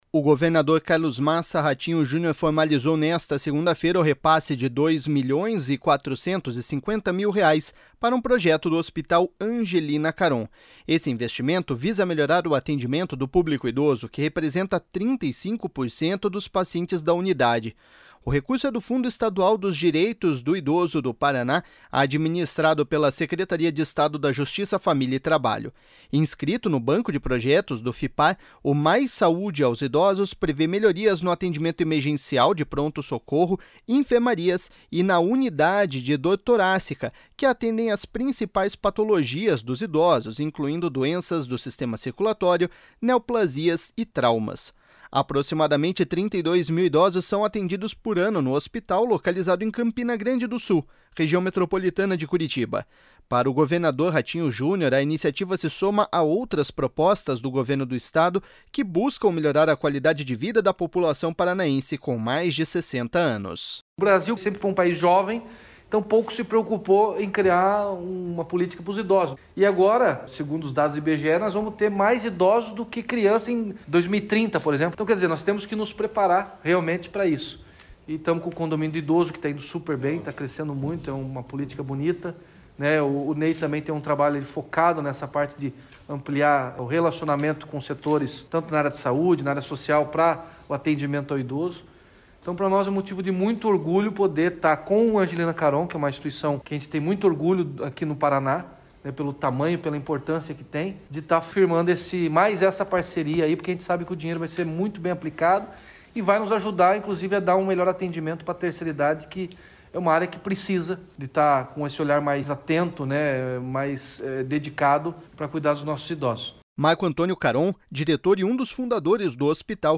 Para o governador Ratinho Junior, a iniciativa se soma a outras propostas do Governo do Estado que buscam melhorar a qualidade de vida da população paranaense com mais de 60 anos.//SONORA RATINHO JUNIOR.//
Secretário estadual da Justiça, Família e Trabalho, Ney Leprevost destacou a relevância do cuidado com este público.// SONORA NEY LEPREVOST.//